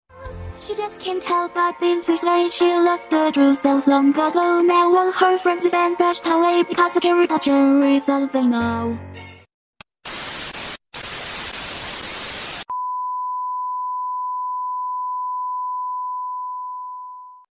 Tw!Massive amount of glitching, static sound effects free download
Tw!Massive amount of glitching, static background/sounds, and long beep!